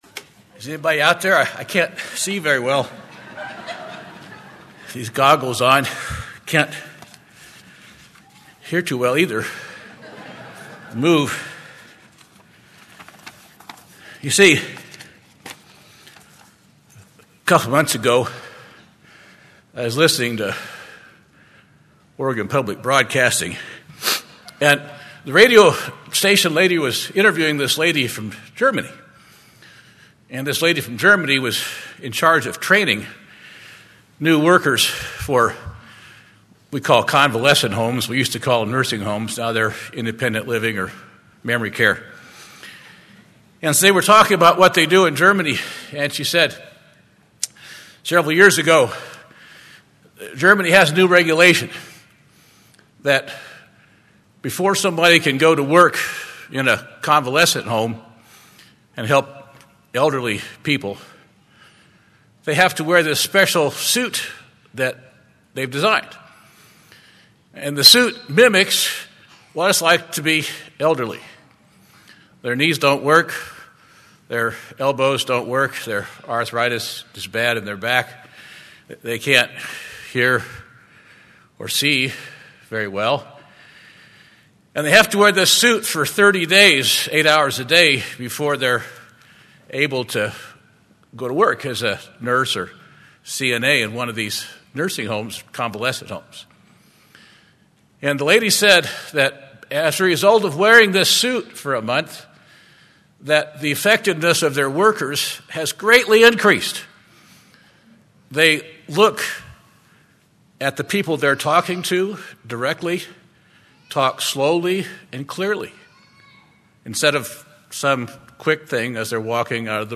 This sermon was given at the Bend-Redmond, Oregon 2018 Feast site.